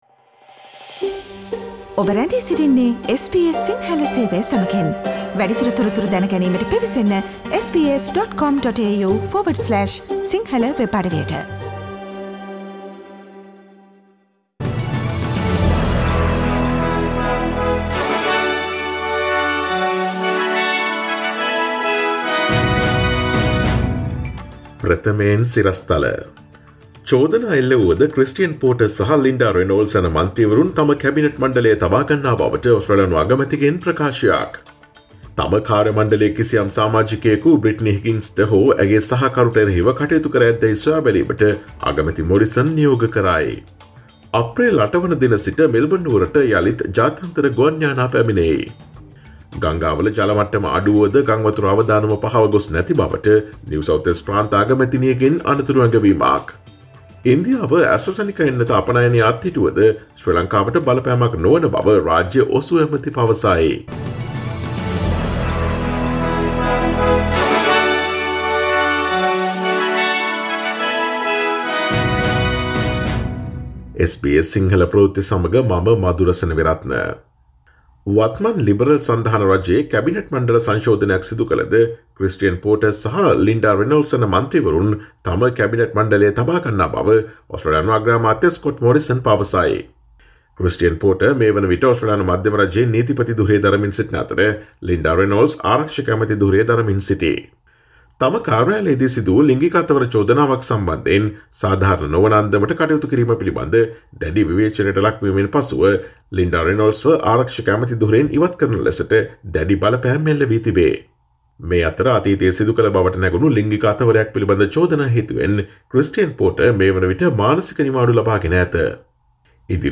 Here are the most prominent Australian and Sri Lankan news highlights from SBS Sinhala radio daily news bulletin on Friday 26 March 2021.